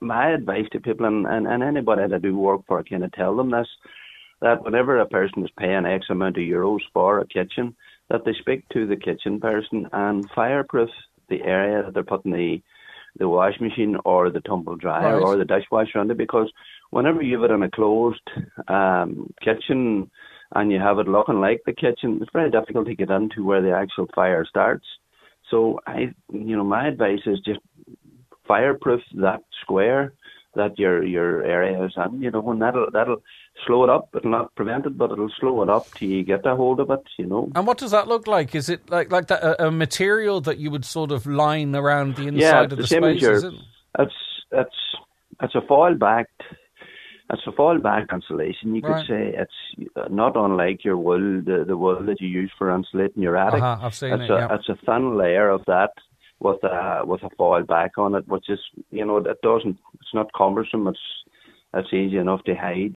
Cllr Canning says while it won’t stop the fire, it will buy more time: